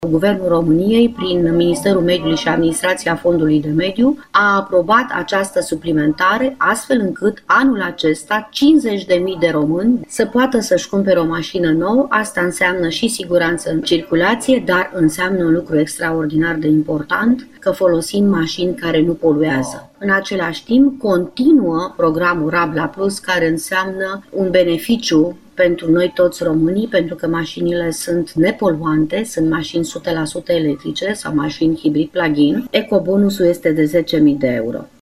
Graţiela Gavrilescu, ministrul Mediului: